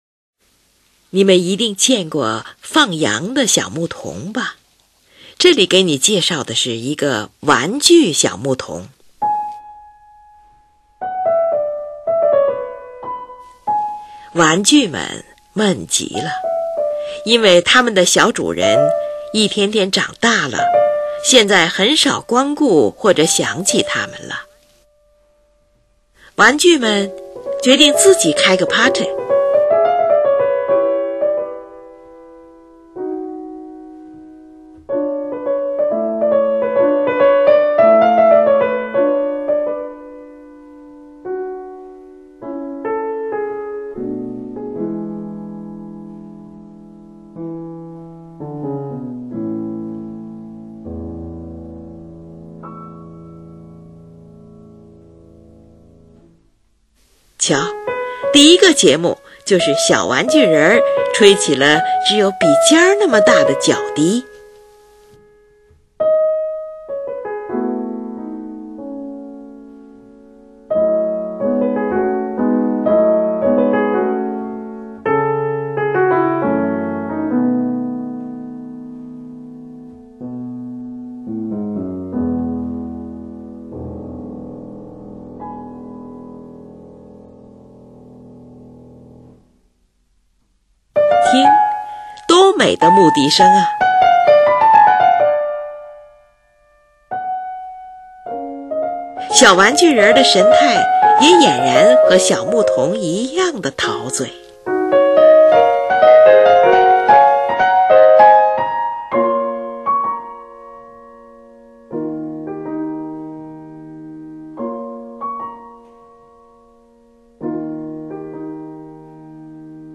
在这支短小的乐曲中，钢琴弹出简单的旋律，因为音区的选择，使声音听上去很像是牧童用小牧笛吹奏出的音色。
整首旋律显得十分的诗意化，有时又有些跳动的音型出现；
中间出现的五声音阶的音调使音乐富于东方神秘的美感。
描绘出一幅静谧而不呆板的乡村画面。